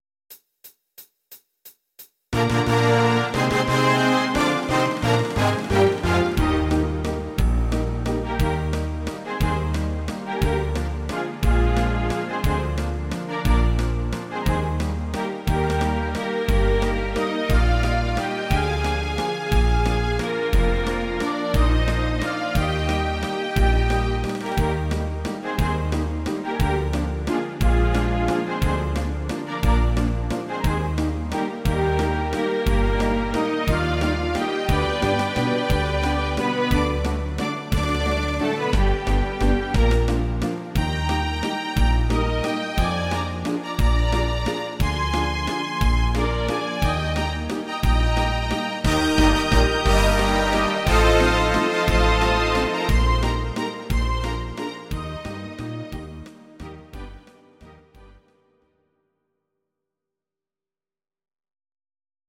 These are MP3 versions of our MIDI file catalogue.
Please note: no vocals and no karaoke included.
instr. Orchester